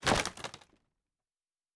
pgs/Assets/Audio/Fantasy Interface Sounds/Wood 06.wav at master
Wood 06.wav